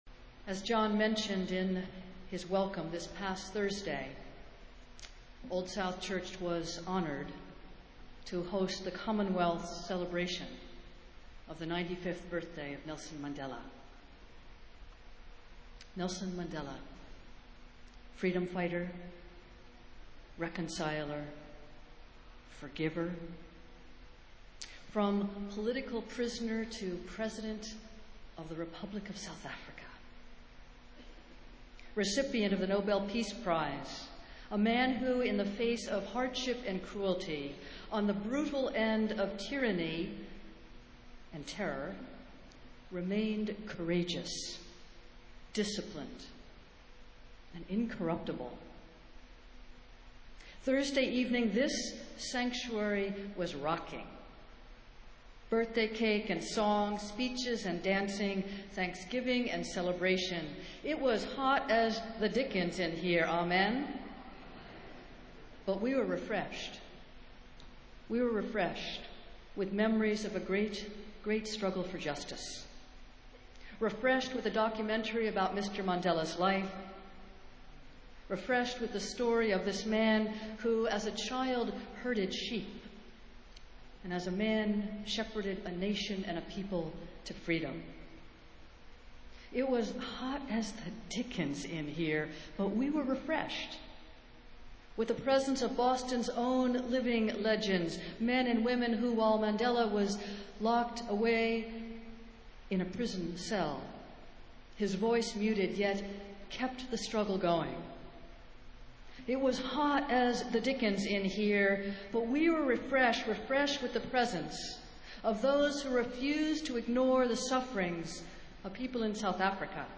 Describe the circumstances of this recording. Festival Worship - Ninth Sunday after Pentecost